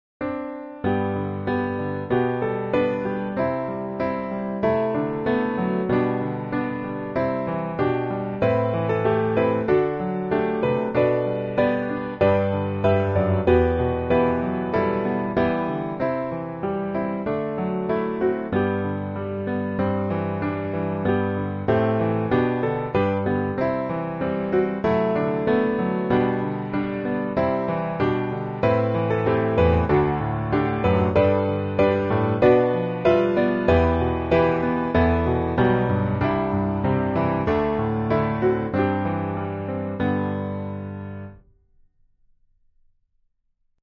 G大調
8.6.8.6. with repeat.